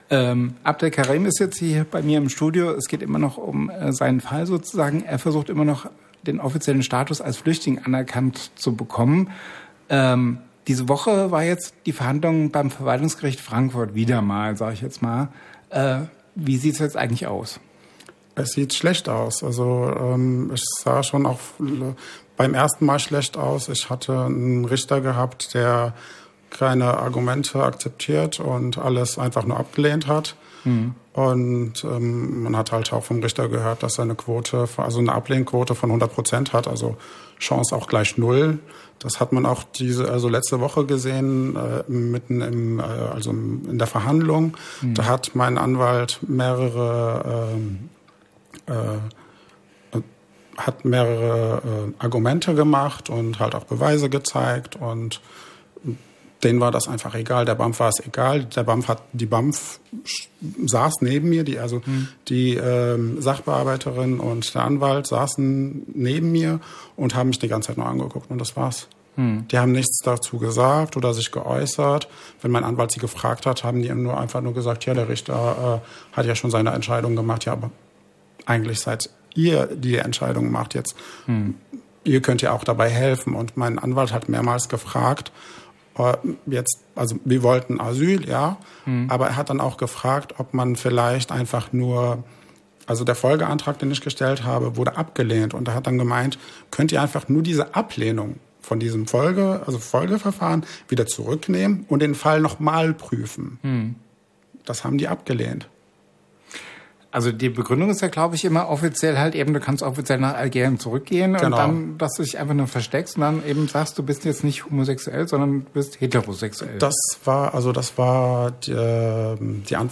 live im Studio